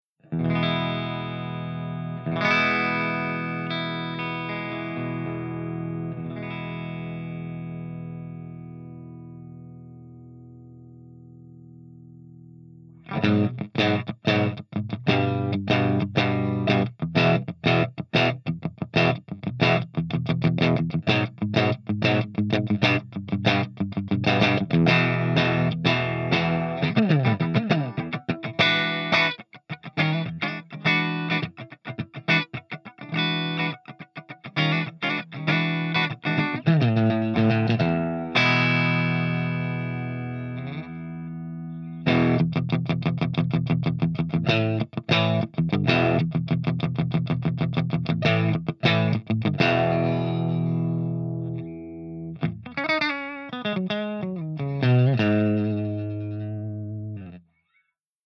114_MARSHALLJCM800_CH1CLEAN_GB_SC